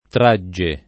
tr#JJe]; ma rarissimo l’inf. traggere; tutte forme, queste con -gg- dolce, sconosciute in generale ai comp. di trarre — per traiamo, antiq. tragghiamo [traggL#mo] — cfr. redigere